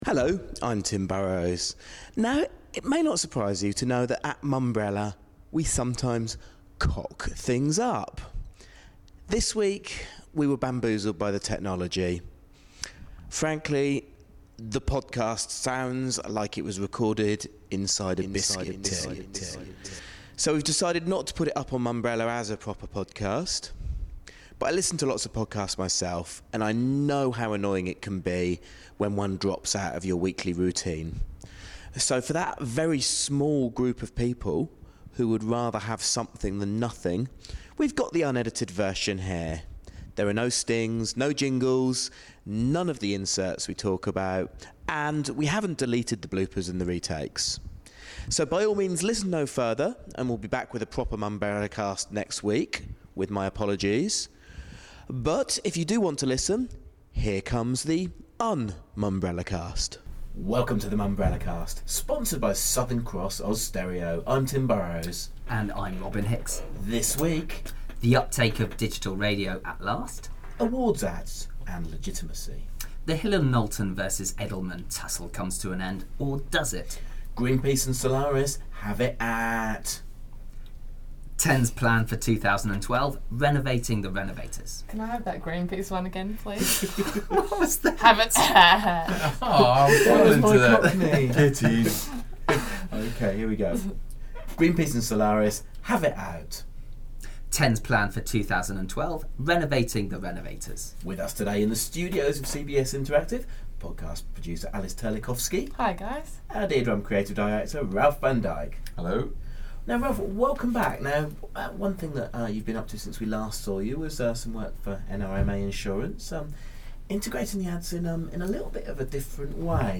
Unfortunately technology got the better of us this week and the sound quality of this Mumbrellacast is average – to say the least.
For the small group of avid Mumbrellacast listeners we have uploaded the podcast in its most raw format. No jingles, no clips and no editing.